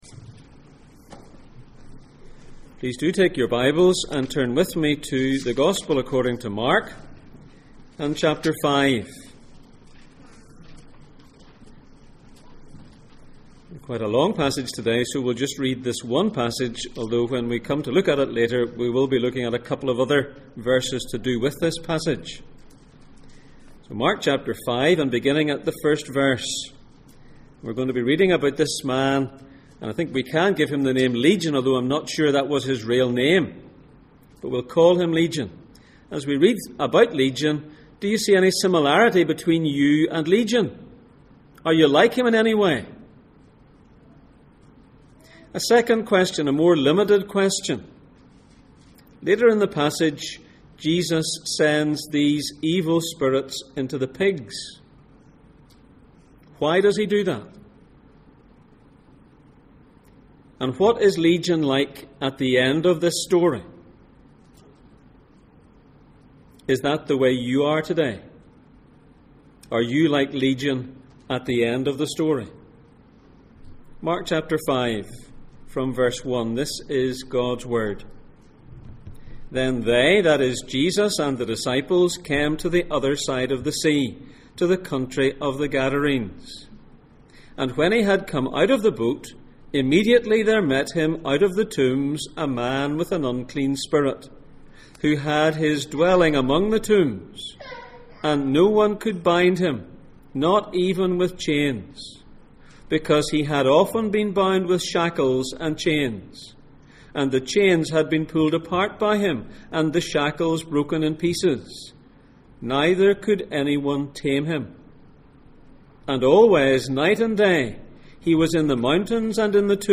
Mark Passage: Mark 5:1-20, Ephesians 2:1-3, Acts 26:13-18 Service Type: Sunday Morning